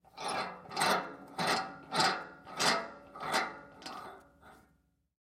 Звук затяжки маховика вентиля